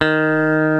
FENDER STRAT 3.wav